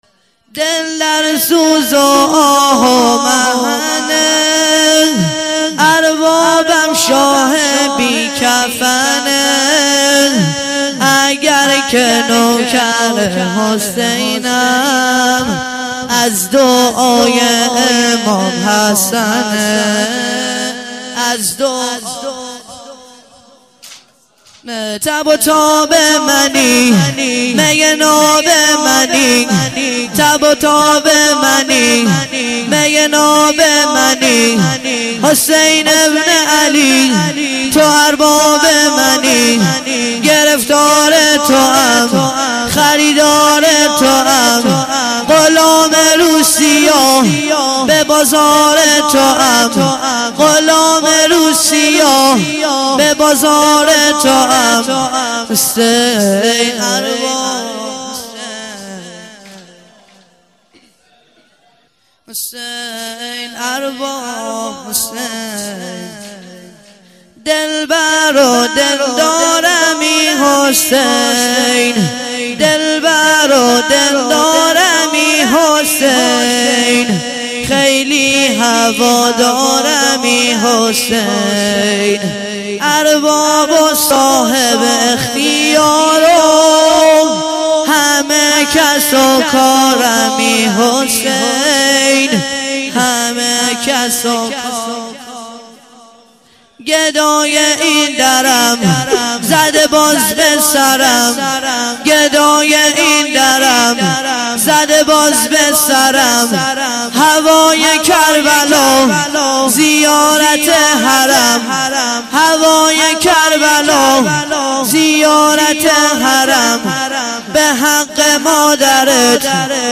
واحد - دل در سوز و آه و محنه